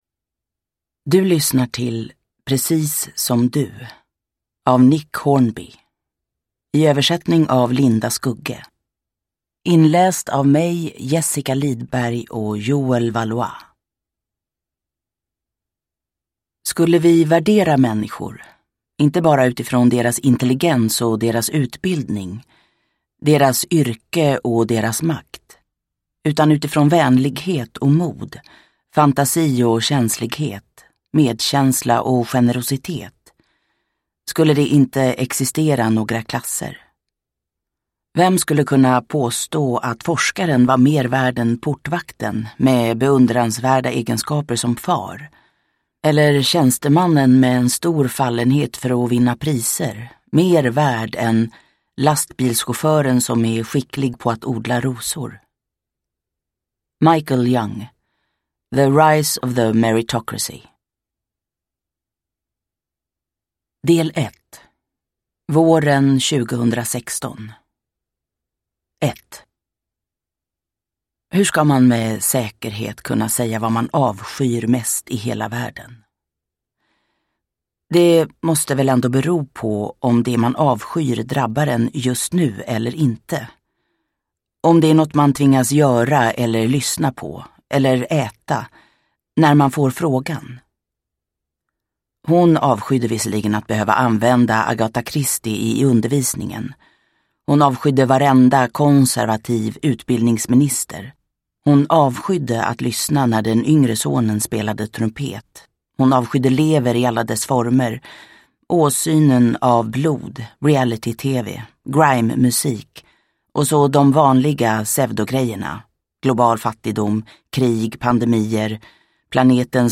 Precis som du – Ljudbok – Laddas ner